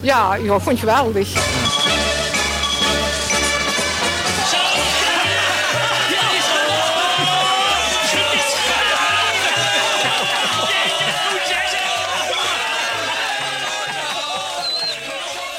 Hier enkele jingles.